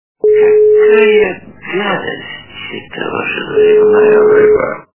» Звуки » Люди фразы » Из к/ф - Какая гадость - эта ваша заливная рыба
При прослушивании Из к/ф - Какая гадость - эта ваша заливная рыба качество понижено и присутствуют гудки.